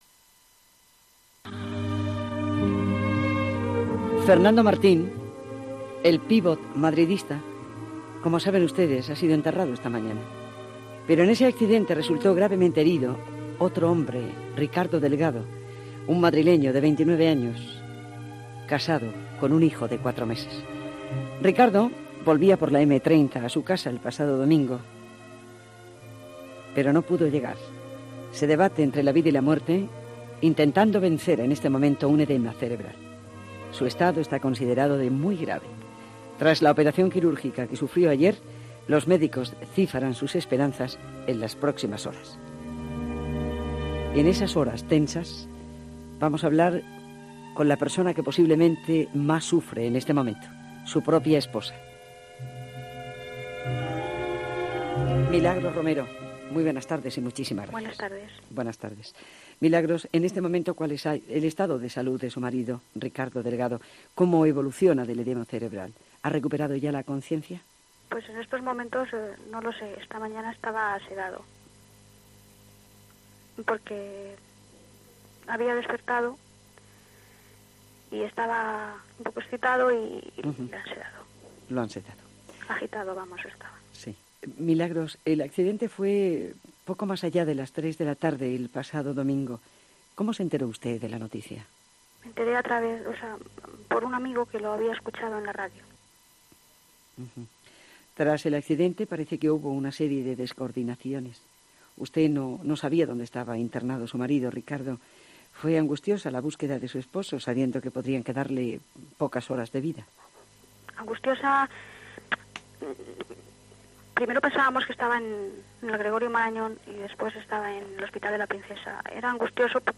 Aquellos fueron unos minutos duros de radio.